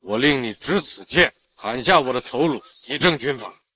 Auktoritär AI för seriösa nyhetsröster
Leverera slagkraftig rapportering med en djup, trovärdig AI-röst utformad för seriösa nyhetssändningar, granskande dokumentärer och brådskande bulletiner.
Auktoritär ton
Inmatningsljud(klicka för att ladda ner)